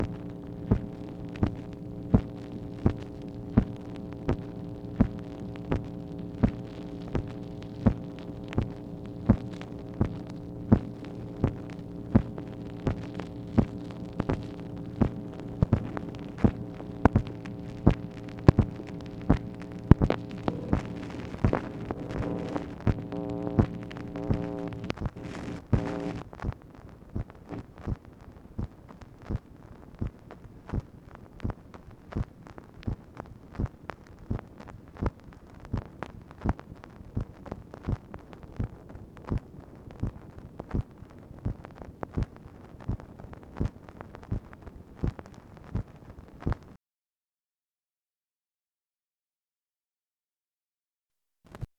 MACHINE NOISE, August 14, 1964
Secret White House Tapes | Lyndon B. Johnson Presidency